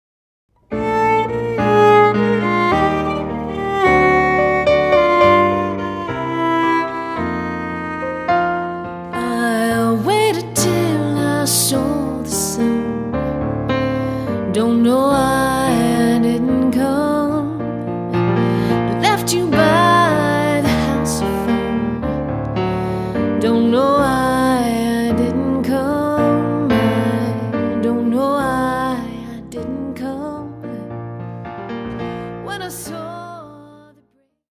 piano
Because of its unique jazz violin/vocalist lineup